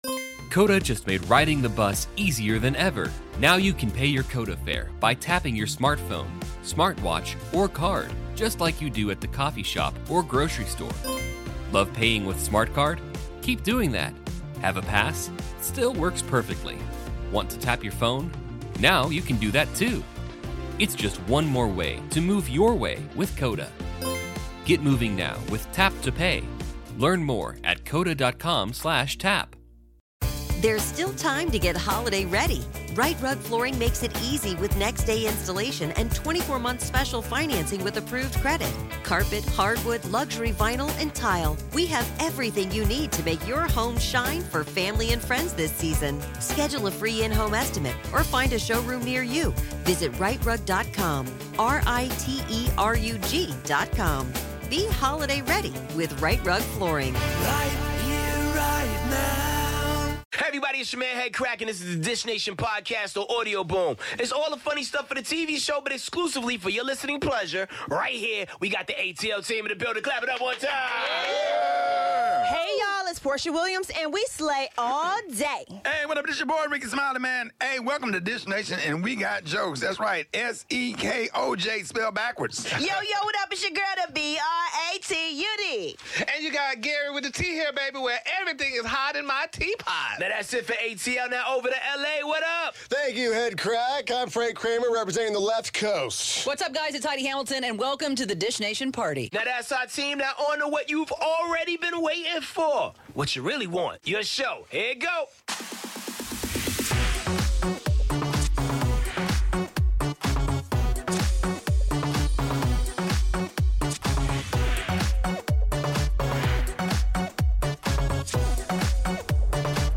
Guest host: Eva Marcille. Is John Cena trying to get back with Nikki Bella? Willow Smith's shocking revelation to her mom & all the latest with Michael B. Jordan, Kristen Stewart, Wiz Khalifa, Katy Perry and more.